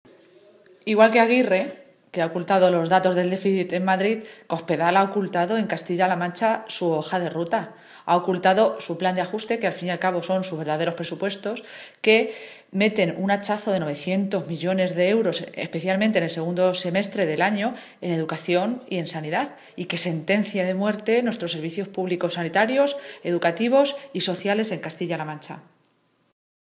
Momento de la rueda de prensa